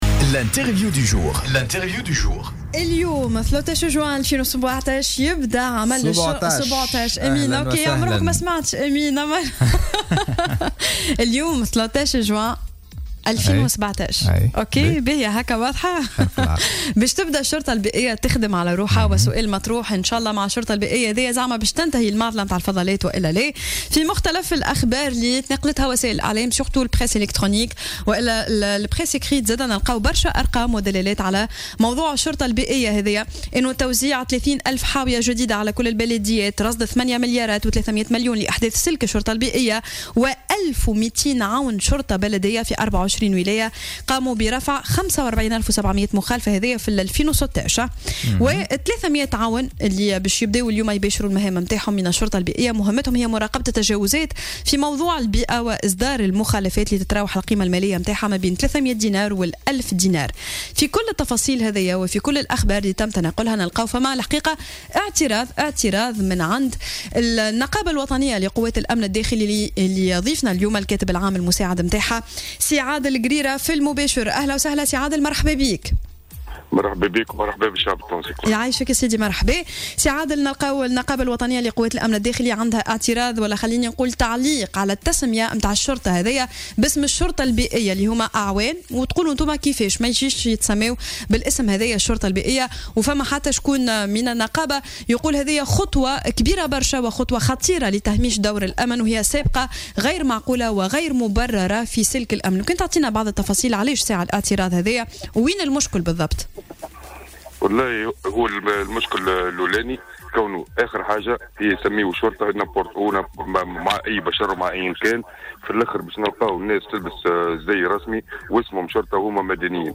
في مداخلة له على الجوهرة "اف ام" صباح اليوم